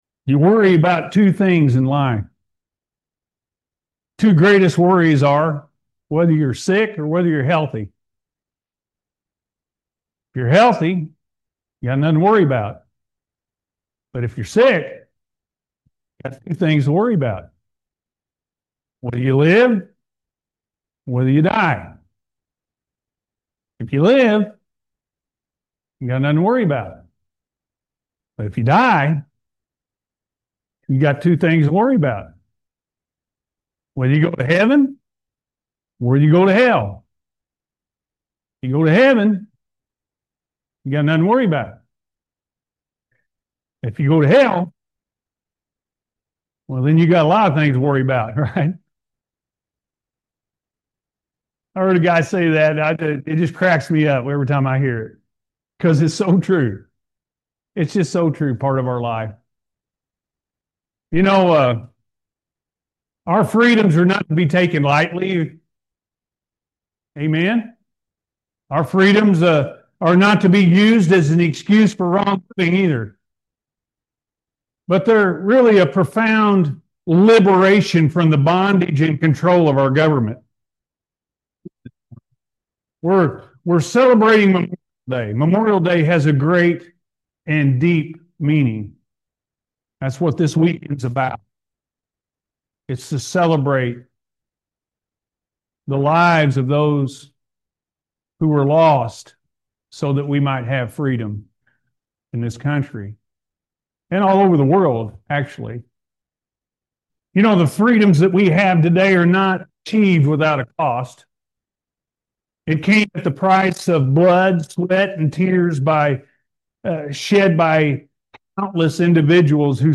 Our Freedom With Christ Comes With Responsibilities-A.M. Service